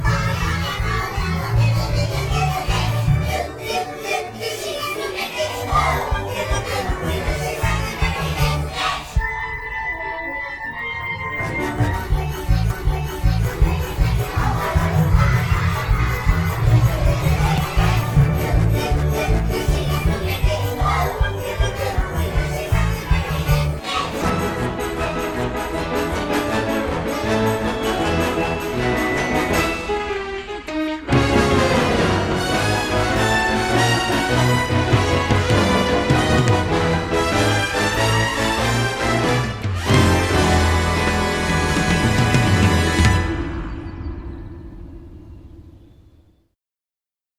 eris_goalhorn.mp3